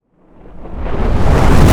cinematic_buildup_reverse_whoosh_01.wav